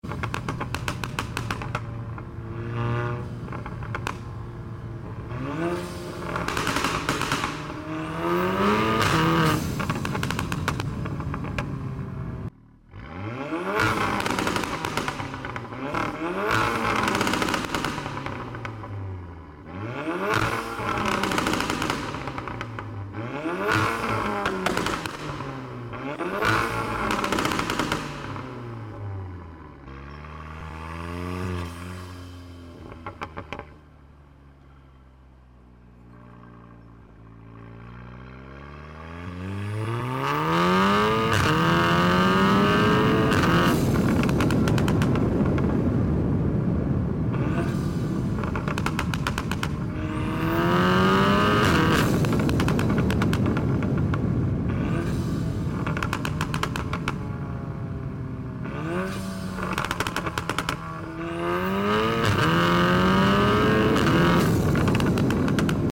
Golf R tunnel run active sound effects free download